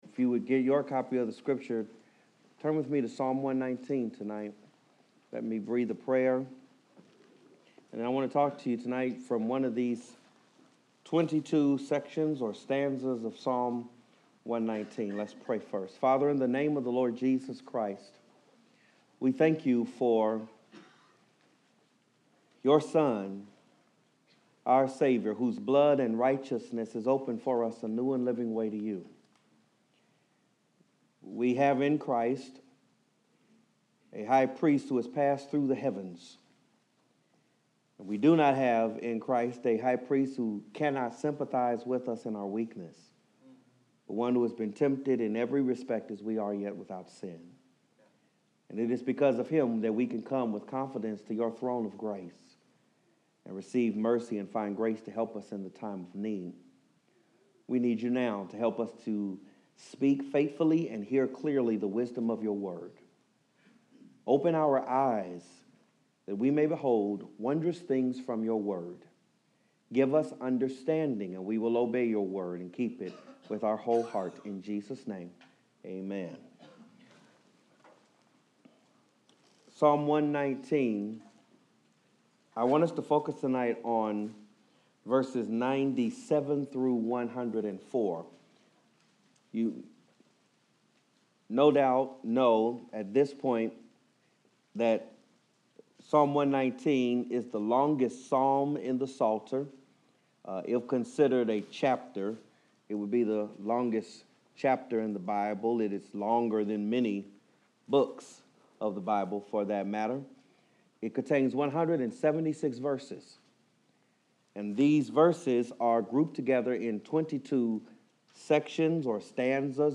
Pulpit Guest Message